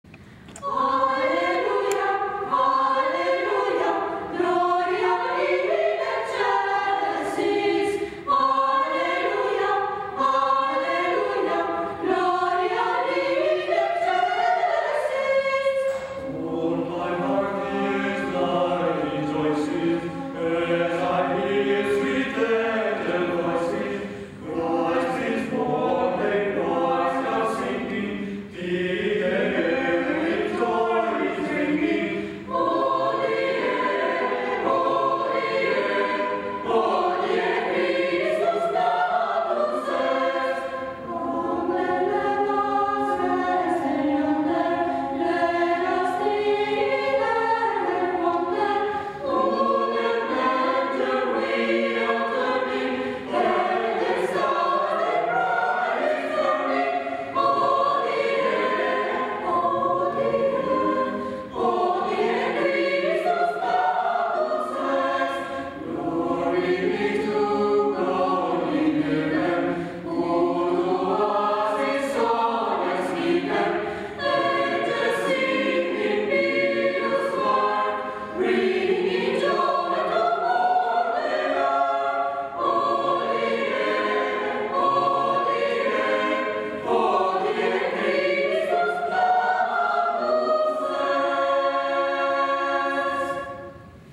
Natalizio William Stevens